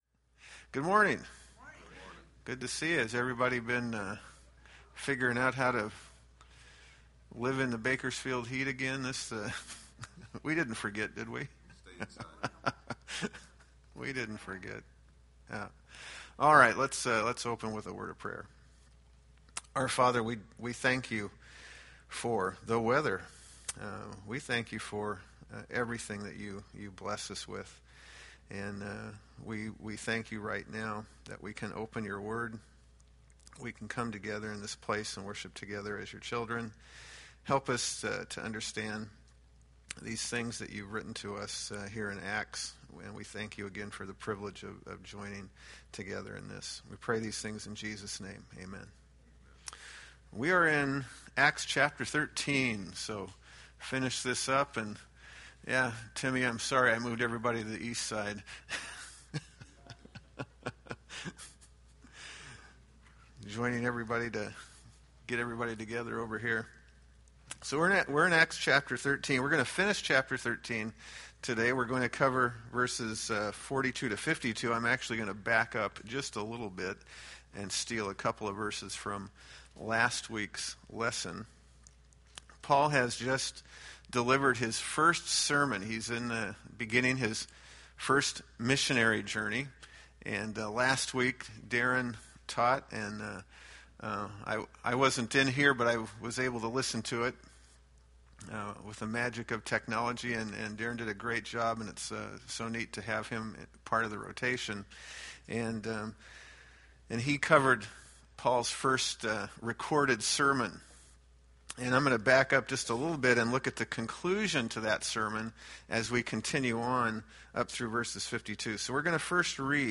Acts Class - Week 30